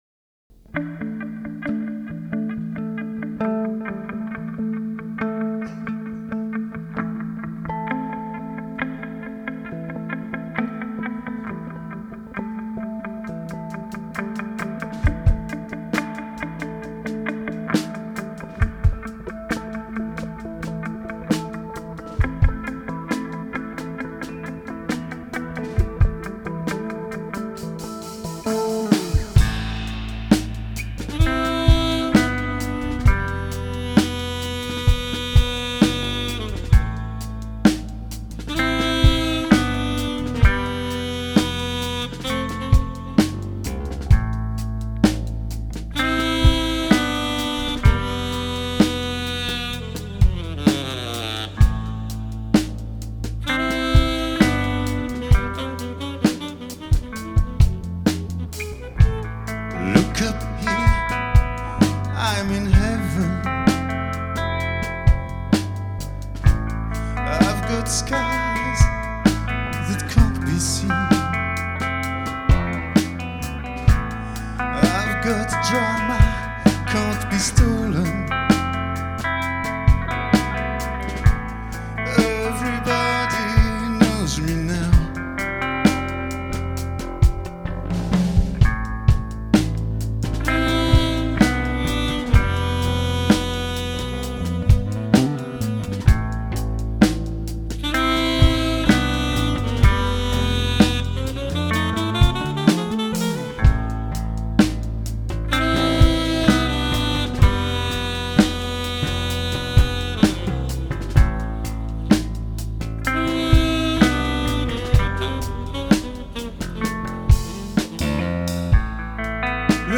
Genre Jazz